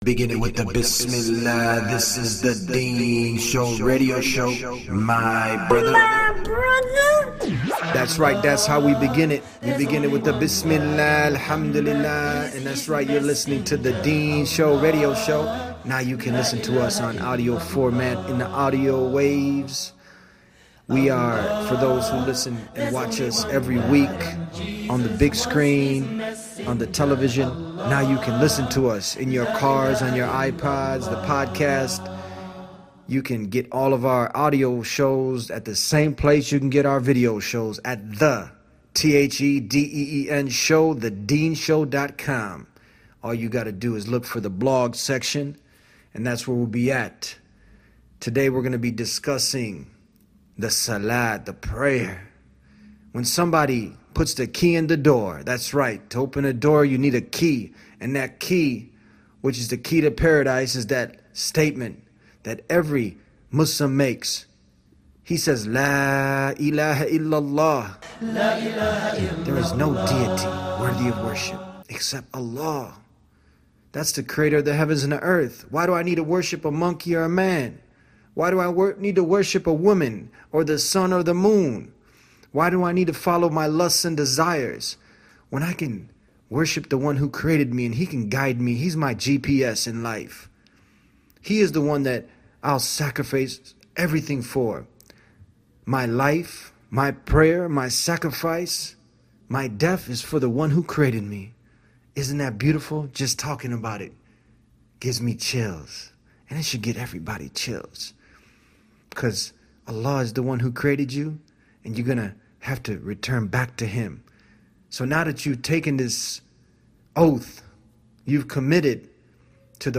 Salah is the lifeline that keeps a Muslim spiritually alive, yet it remains the most commonly neglected obligation among those who claim to believe. This Deen Show radio episode presents the case for why prayer is non-negotiable in Islam, what you stand to gain by establishing it, and what you risk by continuing to ignore the call of your Creator.